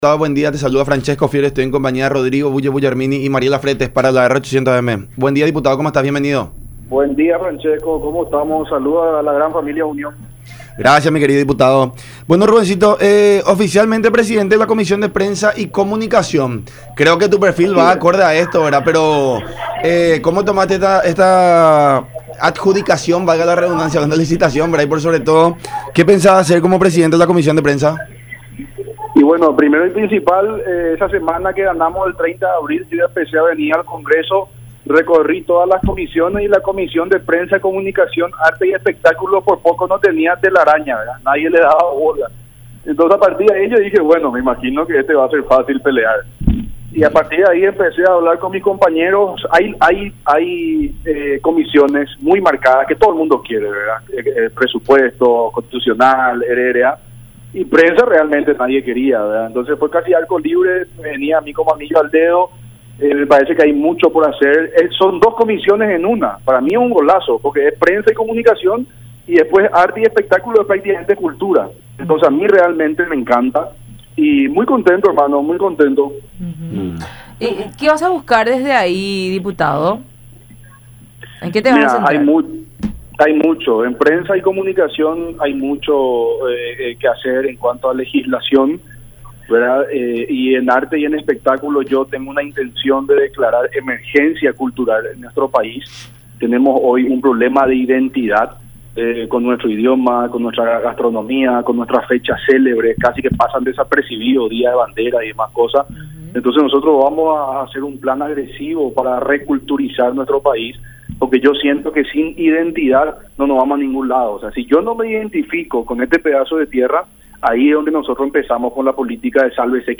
“En prensa hay mucho que hacer en cuanto a legislación y en arte y espectáculo quiero declarar emergencia cultural en el país, tenemos un problema de identidad, haremos un plan agresivo para reculturizar el país”, mencionó Rubén Rubín en el programa “La Unión Hace La Fuerza” por Unión TV y radio La Unión.